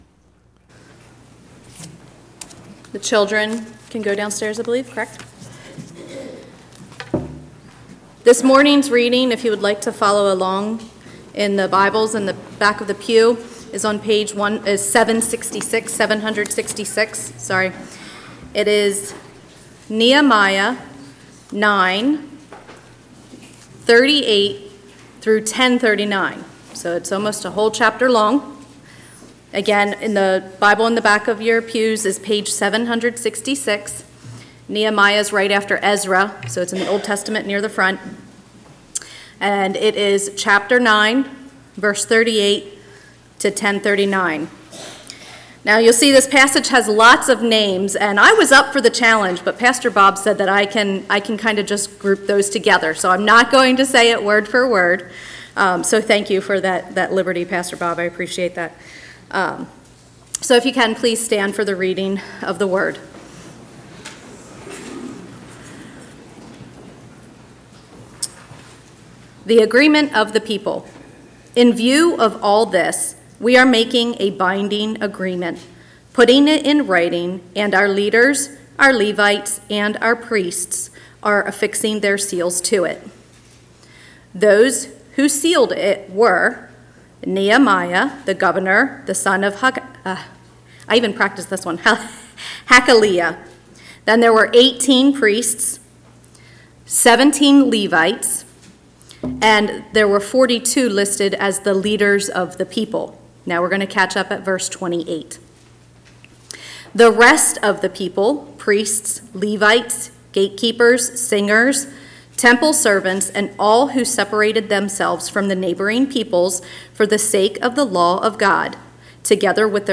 A message from the series "March 2026."